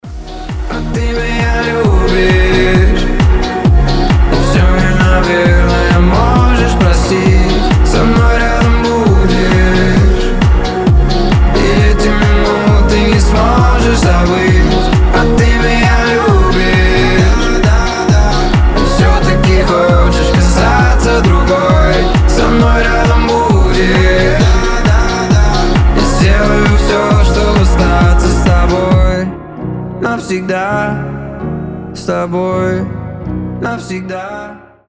• Качество: 320, Stereo
красивый мужской голос
dance